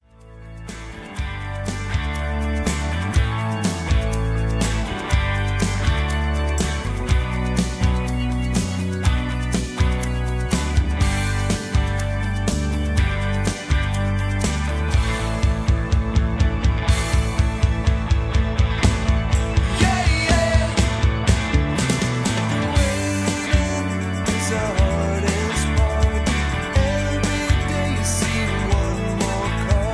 (Key-D) Karaoke MP3 Backing Tracks
Just Plain & Simply "GREAT MUSIC" (No Lyrics).